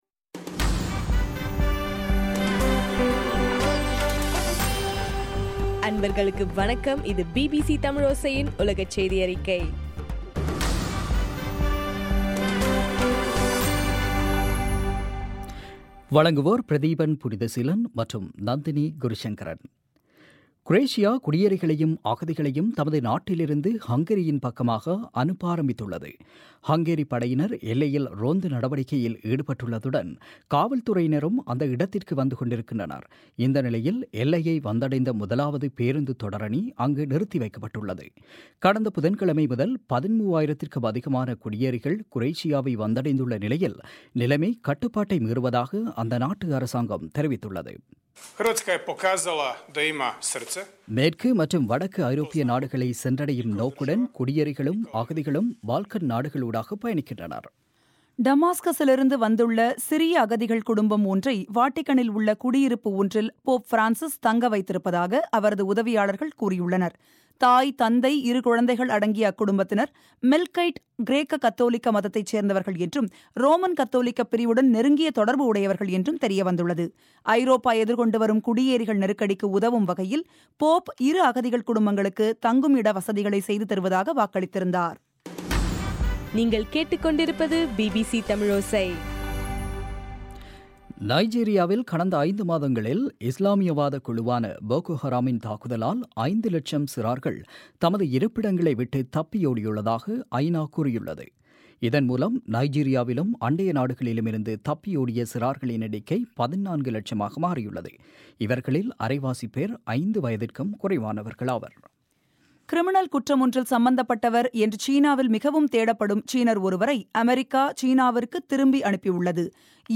இன்றைய ( செப்டம்பர் 18) பிபிசி தமிழ் செய்தியறிக்கை